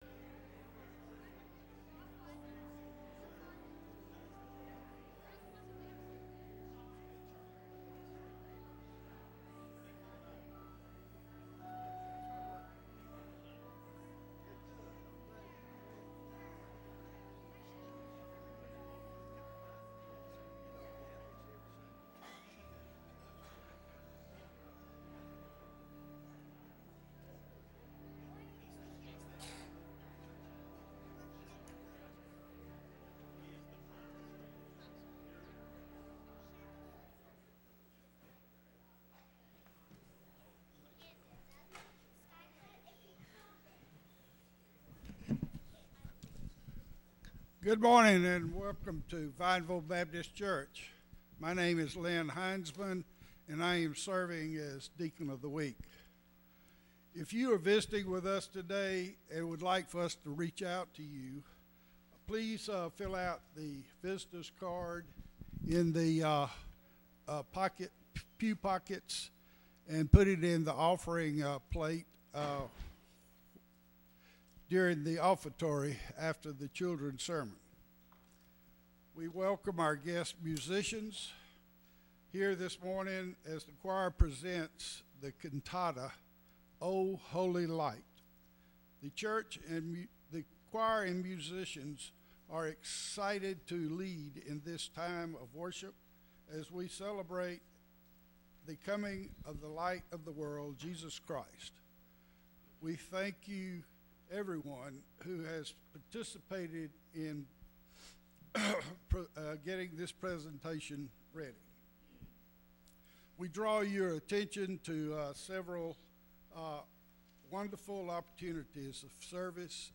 Christmas Cantata